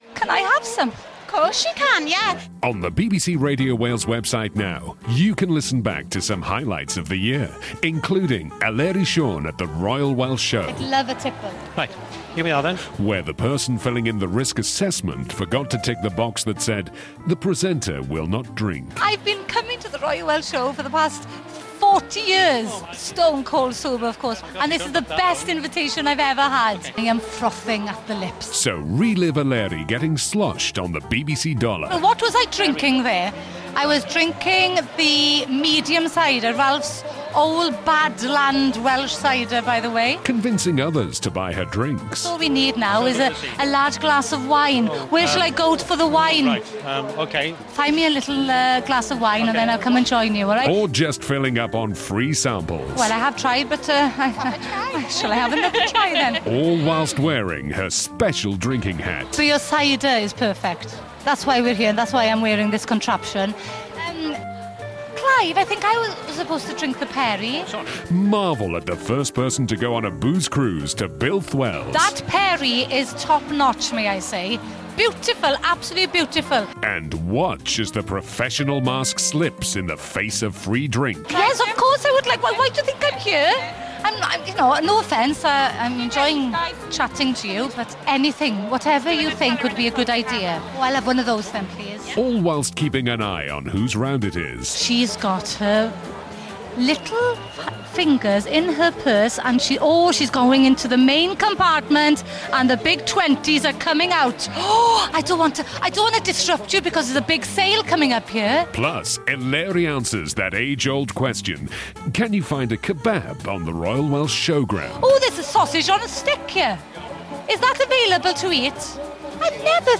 samples freebies at the Royal Welsh Show